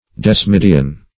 Search Result for " desmidian" : The Collaborative International Dictionary of English v.0.48: Desmid \Des"mid\, Desmidian \Des*mid"i*an\, n. [Gr. desmo`s chain + e'i^dos form.]